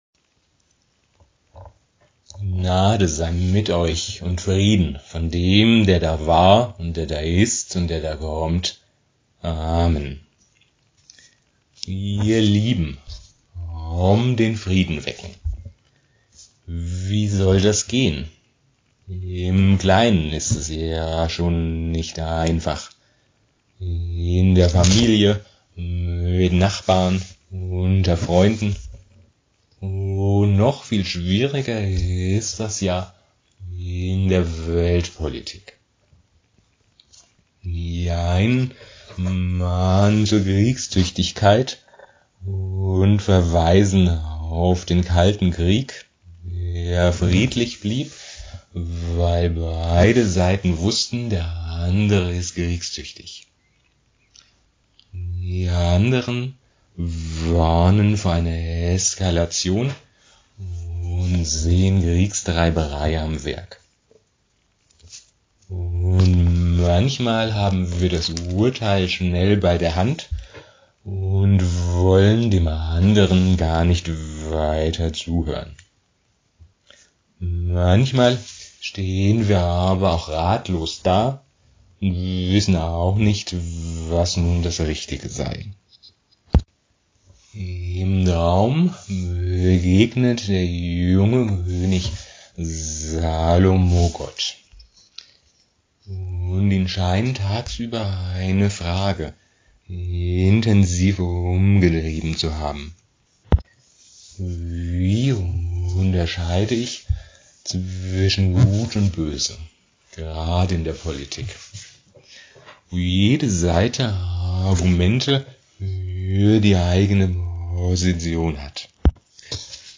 Predigt zum Nachhören Predigt zum Nachlesen: Predigt mit 1.